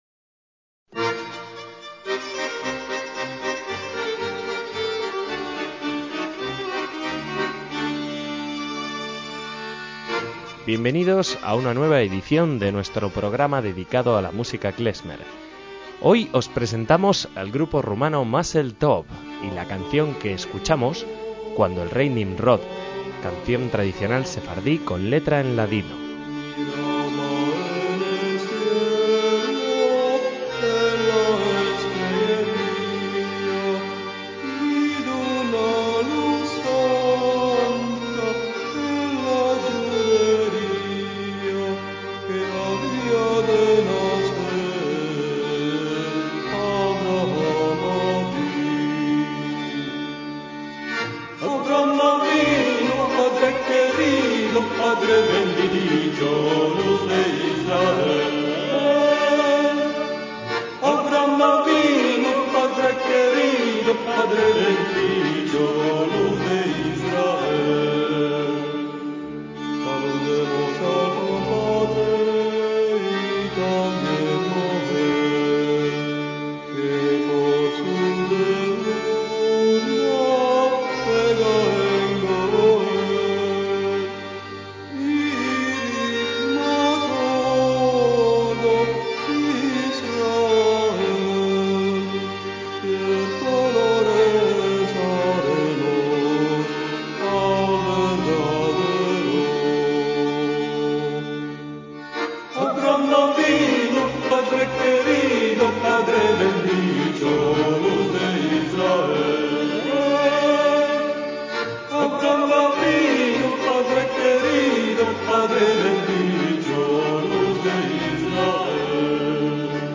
MÚSICA KLEZMER - La banda de músicas tradicionales judías
violinista
cantante